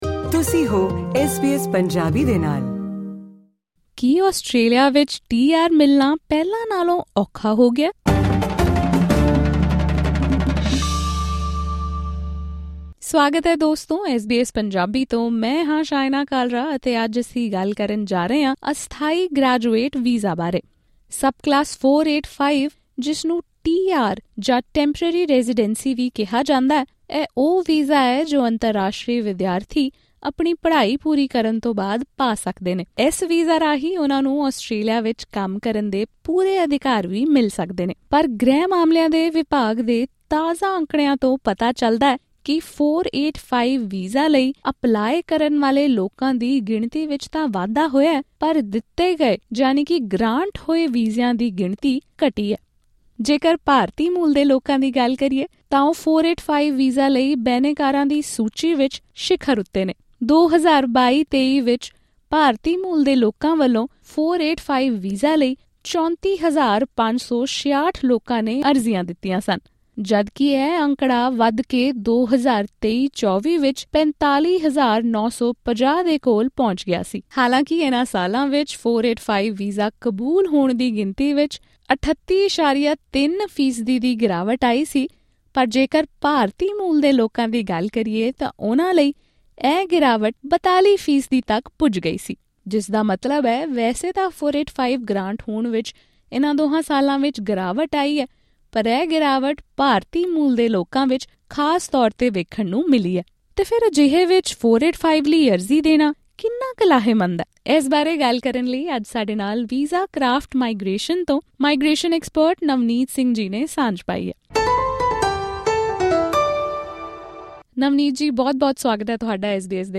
ਖ਼ਾਸ ਗੱਲਬਾਤ